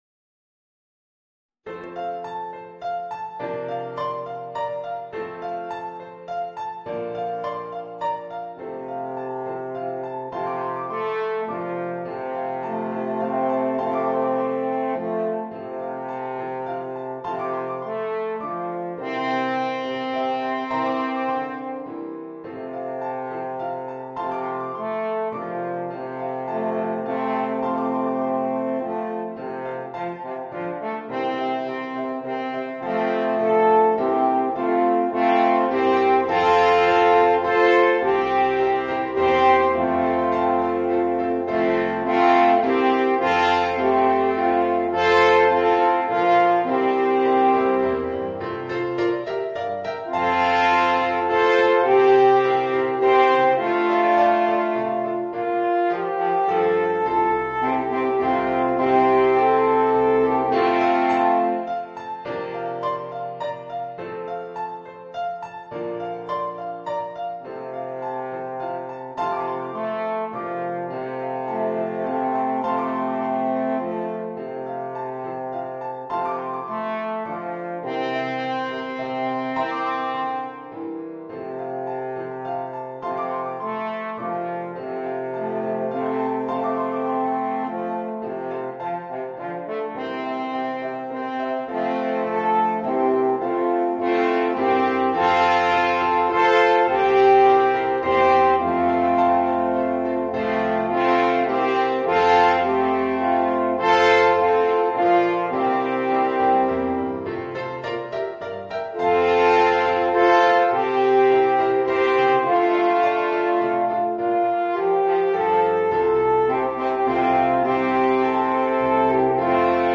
Voicing: 2 Alphorns